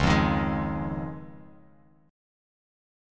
Dbadd9 chord